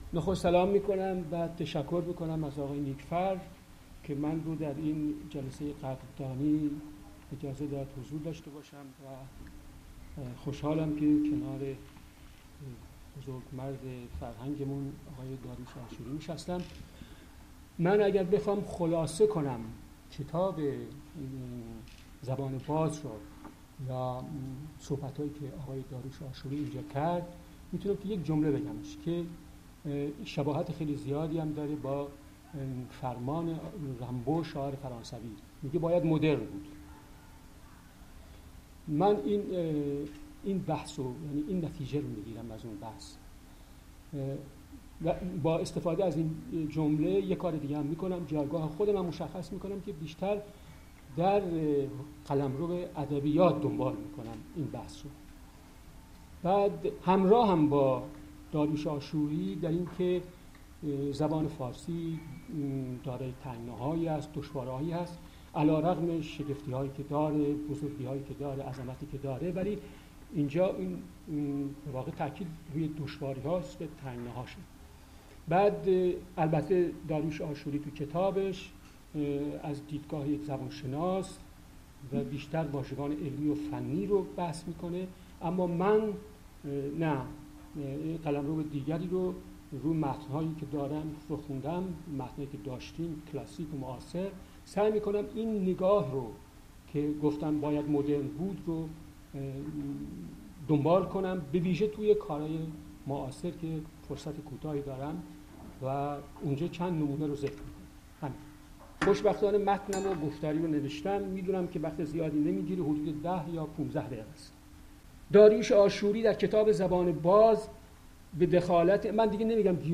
Nasim_Khaksar_speech_Dariush_Ashoori-event.mp3